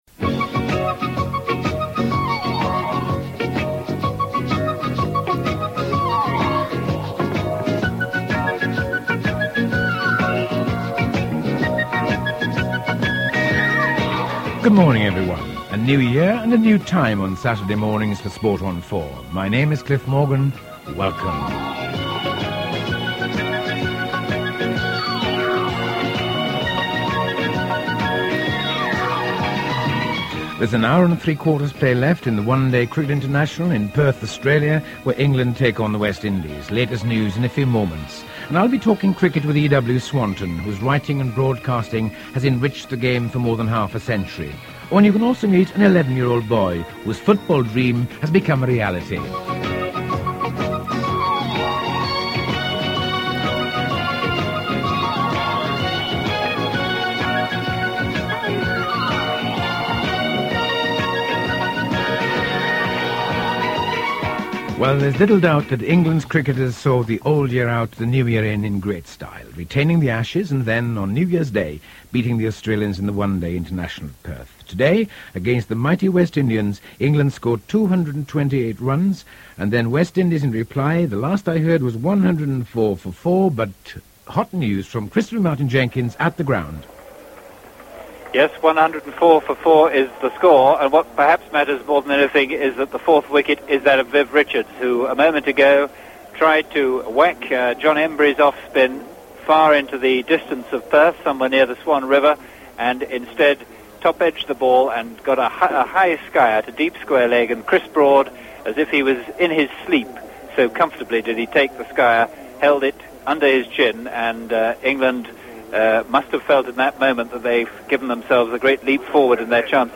Here’s Cliff on 3 January 1987 with clips from the programme and an interview with journalist and sports commentator E.W. Swanton.